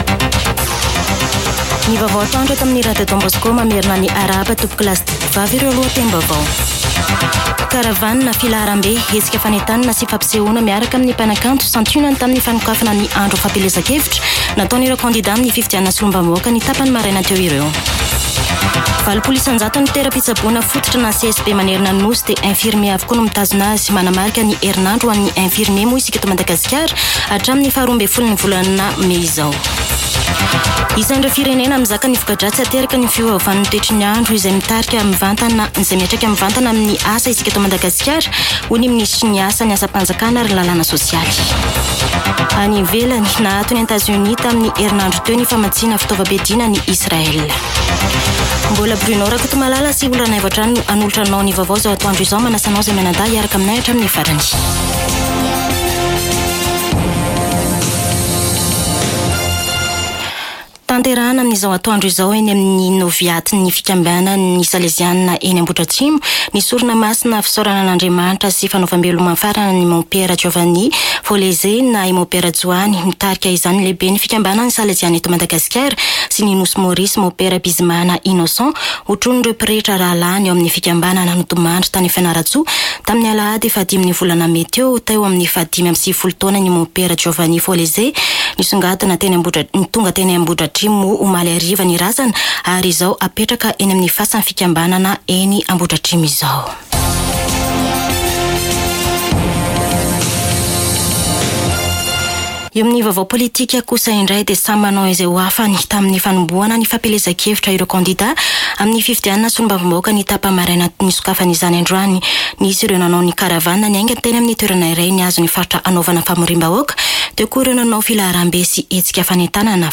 [Vaovao antoandro] Alarobia 8 mey 2024